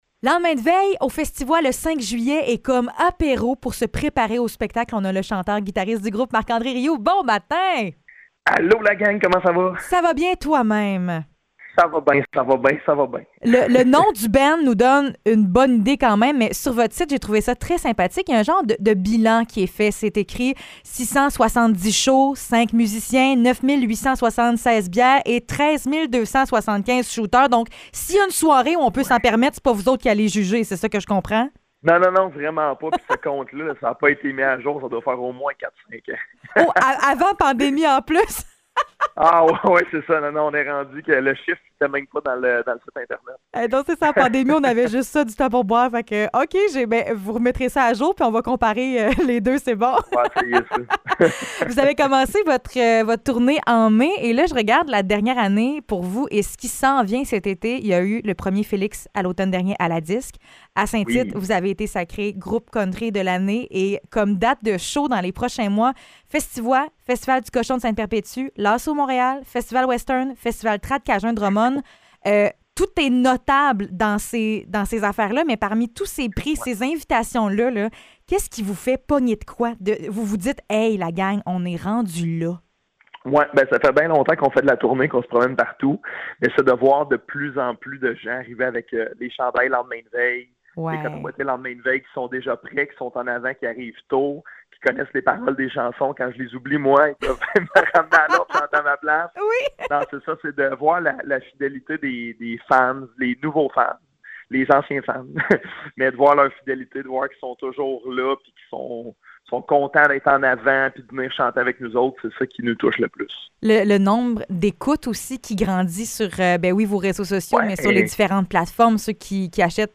Entrevue avec le groupe Lendemain de veille!